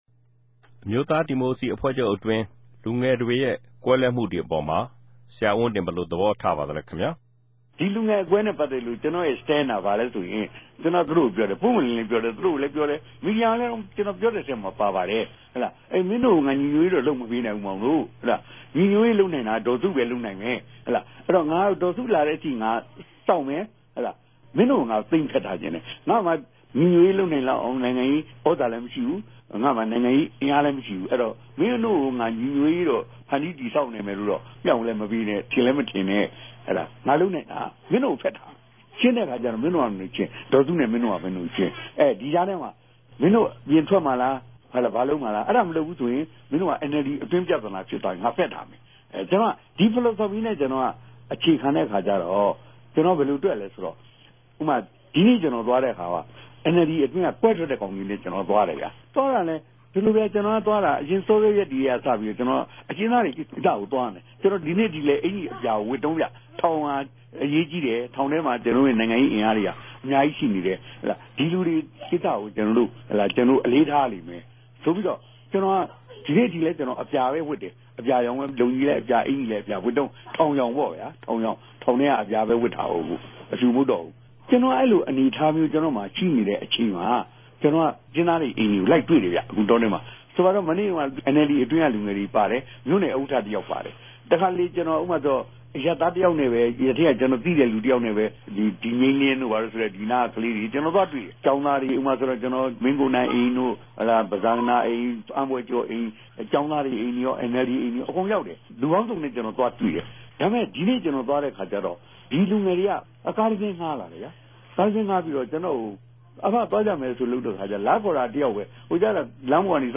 ဦးဝင်းတင်ရဲႚ ေူပာစကား။